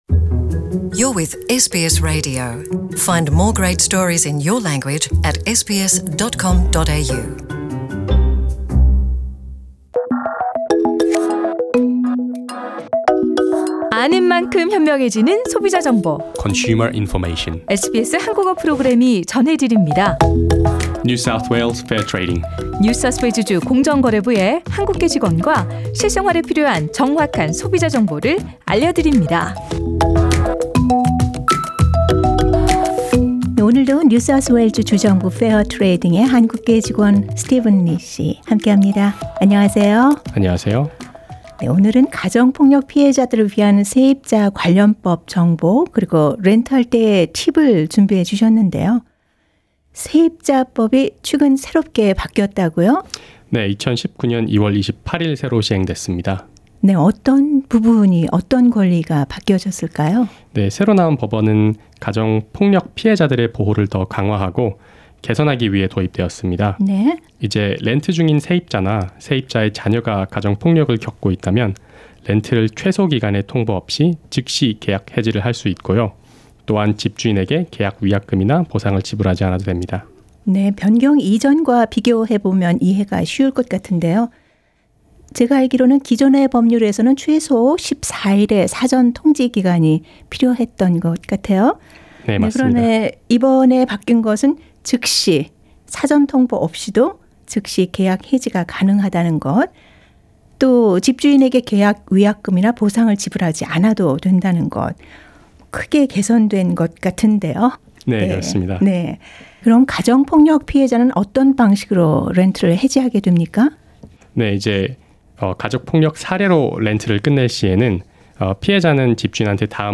Consumer Information is a radio segment brought by NSW Fair Trading, the consumer protection agency.